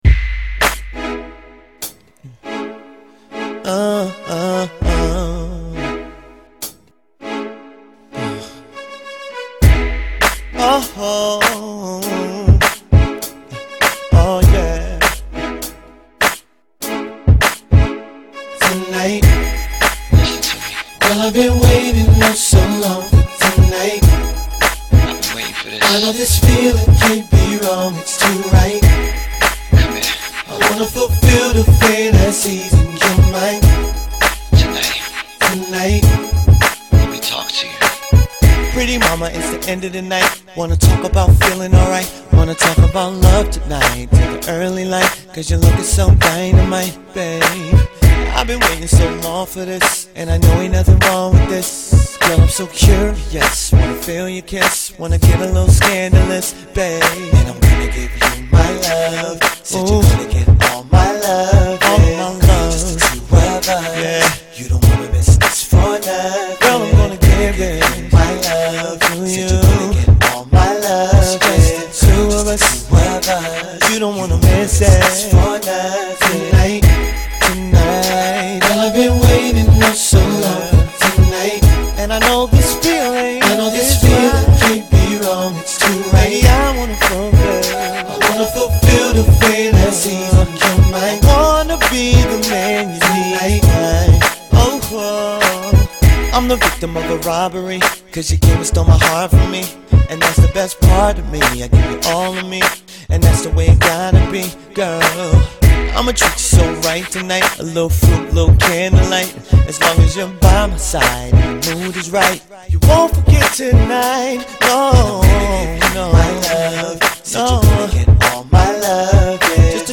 Here’s a new one from the singer/producer/Harvard alum.
Tight beat, tight lyrics